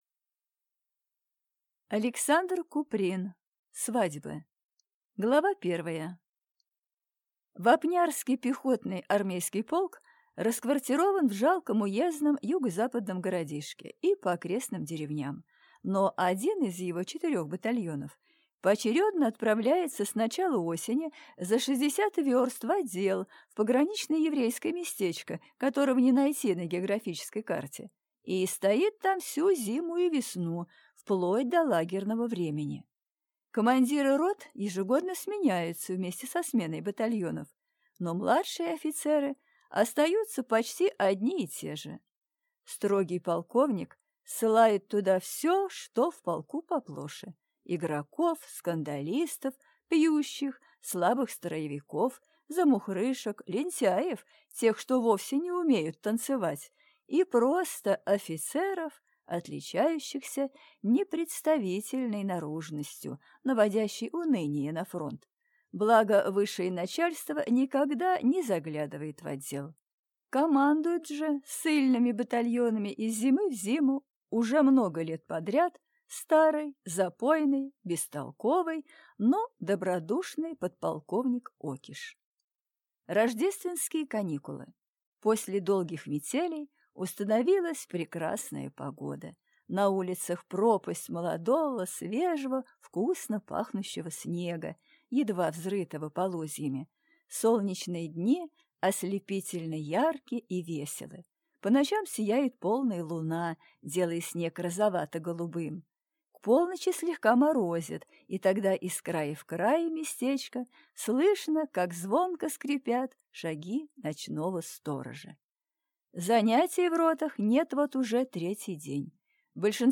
Аудиокнига Свадьба | Библиотека аудиокниг